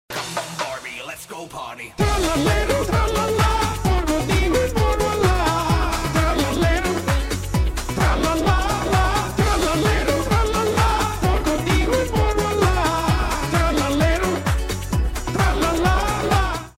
ремиксы , mashup
прикольные , веселые